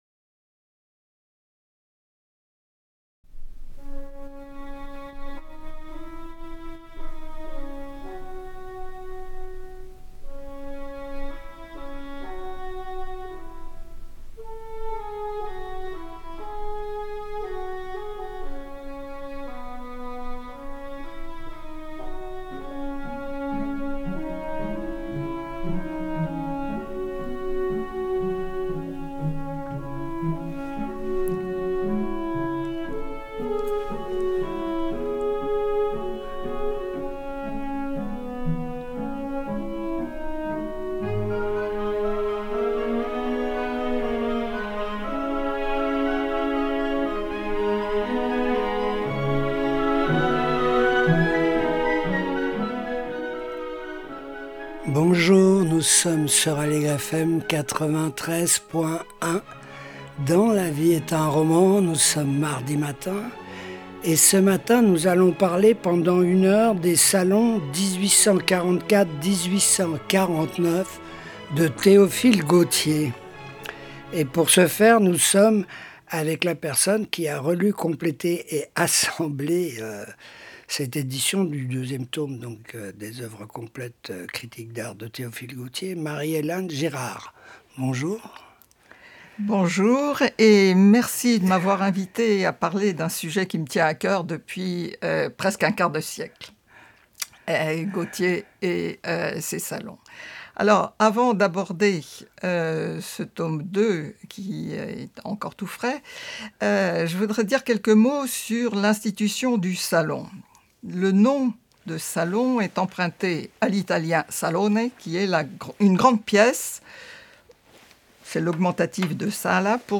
Exposé